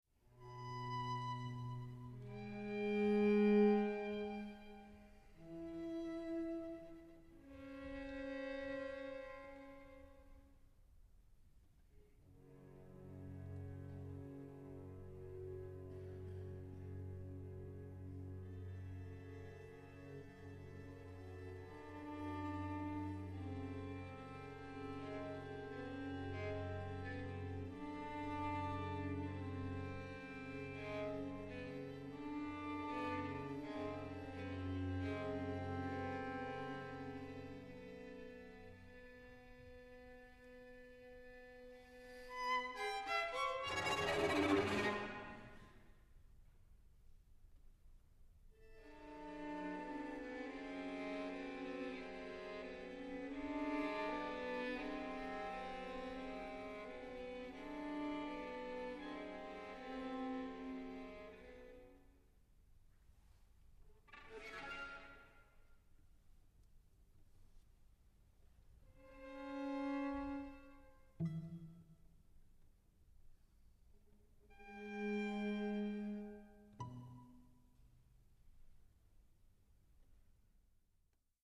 Streichquartett